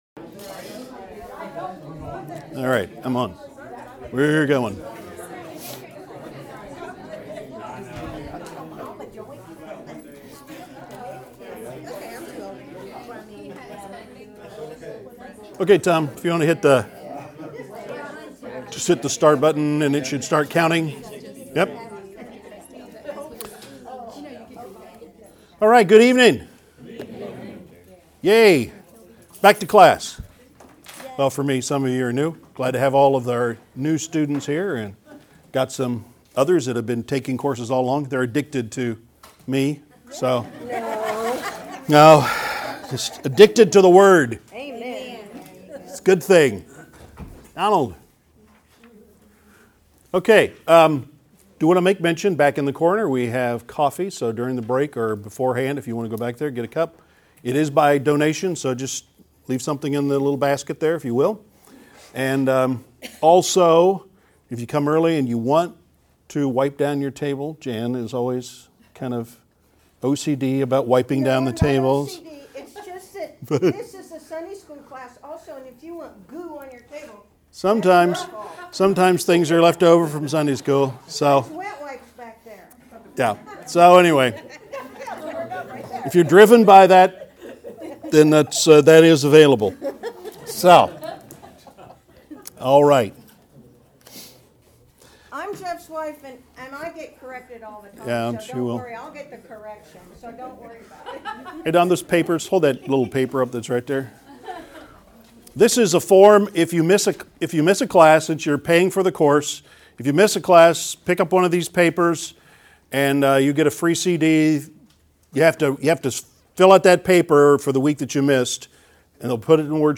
We hope you enjoy this series of teaching.